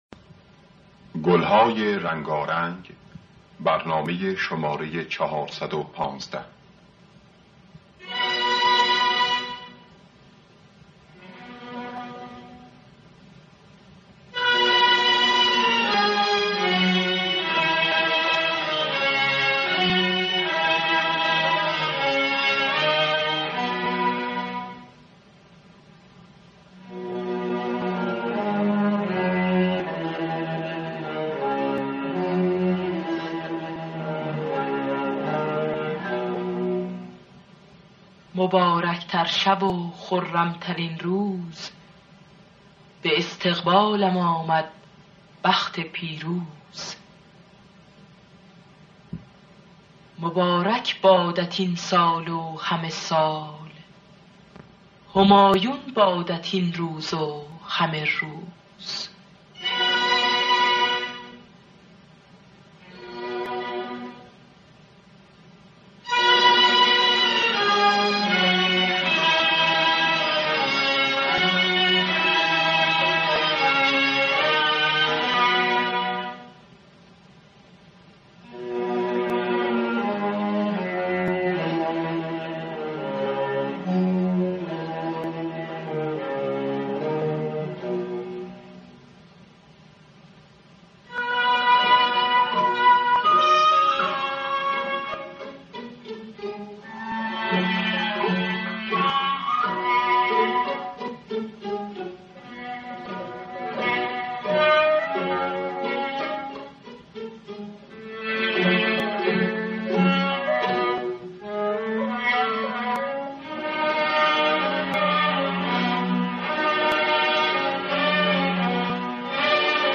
خوانندگان: پروین حسین قوامی نوازندگان: پرویز یاحقی جواد معروفی